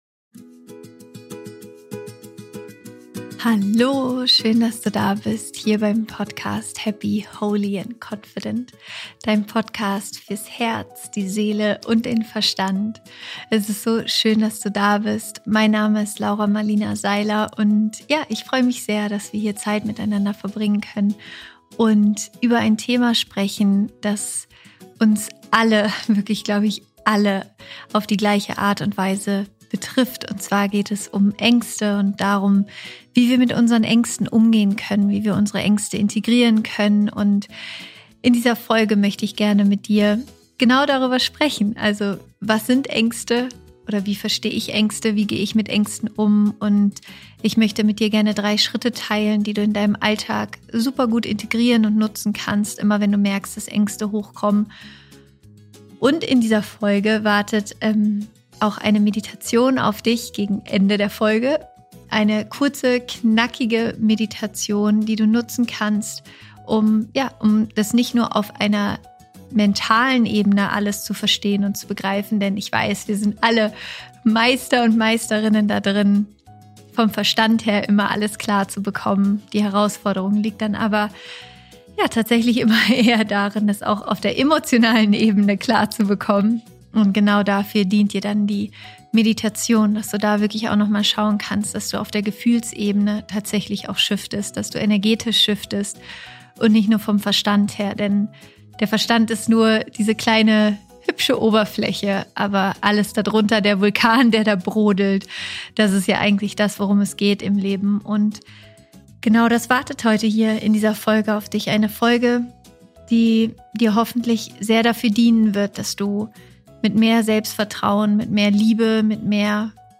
Es geht darum Ängste zu fühlen, sie zu sehen, zu integrieren und schließlich aufzulösen, damit du wieder mit mehr Vertrauen und Leichtigkeit durch dein Leben gehen kannst. Am Ende machen wir gemeinsam eine sehr kraftvolle Meditation, die dich dabei unterstützt, deine Ängste nicht nur auf der körperlichen, sondern auch auf der emotionalen Ebene zu shiften, damit du mit einem neuen Vertrauen in dich deinen Weg gehen kannst.